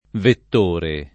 Vittore [vitt1re; non v&t-] pers. m. — in antico, anche Vettore [